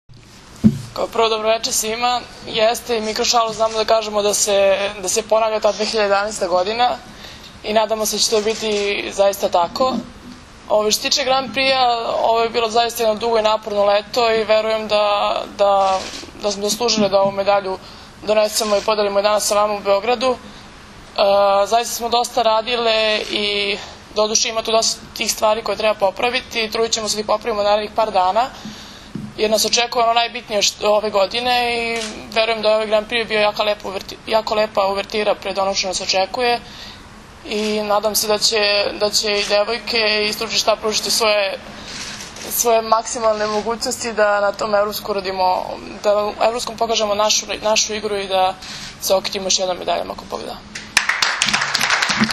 U salonu „Beograd“ aerodroma „Nikola Tesla“ održana je konferencija za novinare, na kojoj su se predstavnicima medija obratili Maja Ognjenović, Brankica Mihajlović, Milena Rašić, Jovana Brakočević i Zoran Terzić.
IZJAVA MILENE RAŠIĆ